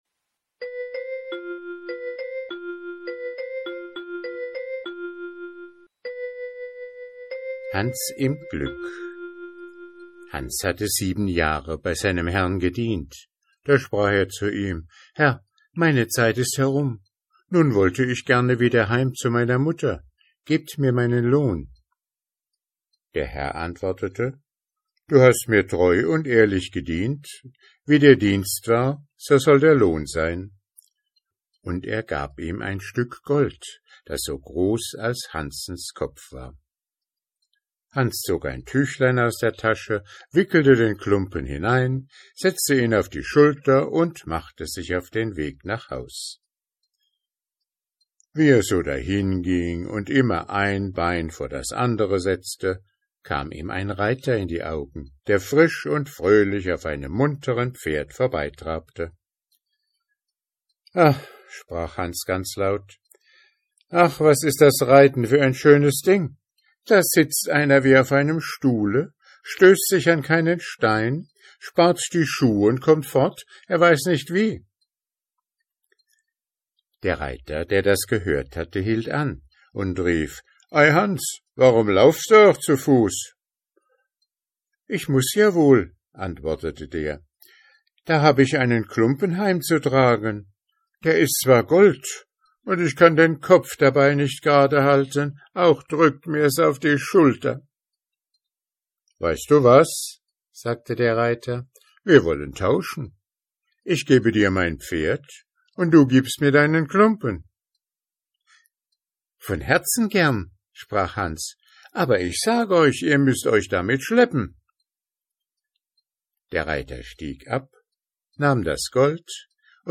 Vorlesezeit 15 min ✓ Alle Grimm und Andersen Märchen in Originalfassung ✓ Online Märchenbuch mit Illustrationen ✓ Nach Lesedauer sortiert ✓ Mp3-Hörbücher ✓ Ohne Werbung